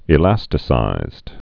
(ĭ-lăstĭ-sīzd)